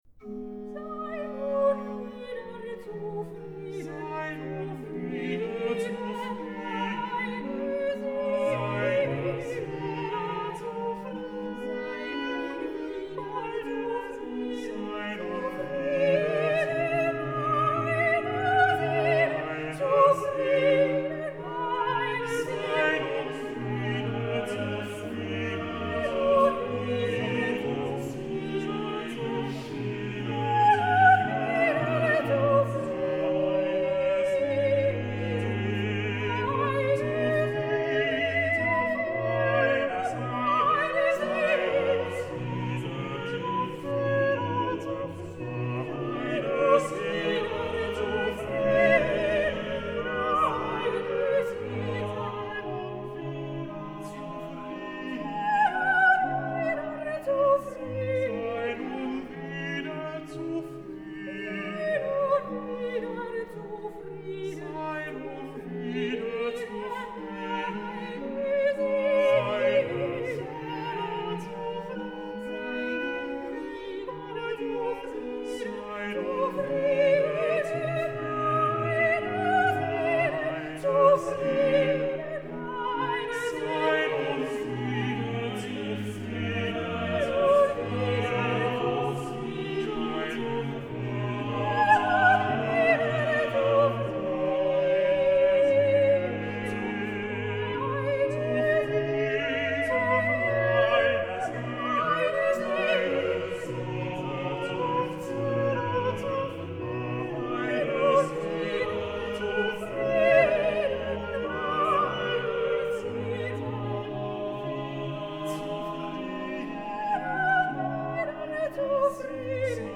17 Coro_ Sei nun wieder zufrieden.mp3